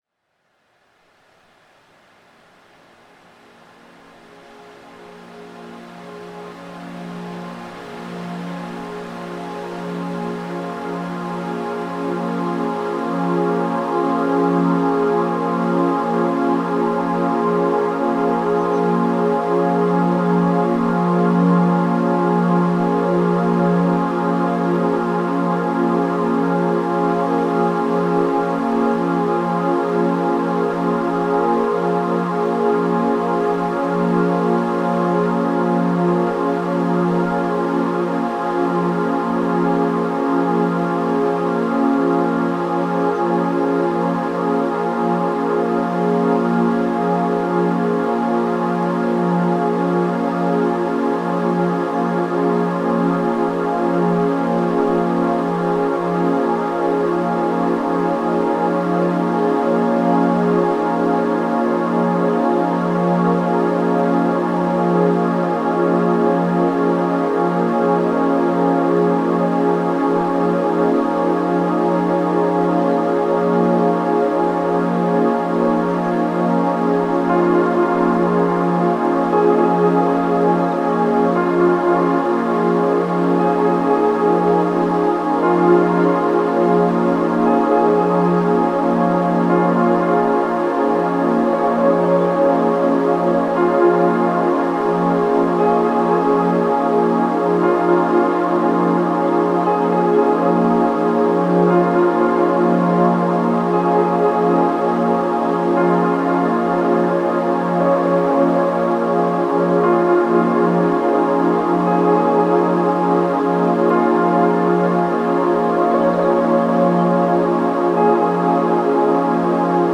Genre: Ambient/Deep Techno/Dub Techno/Electro.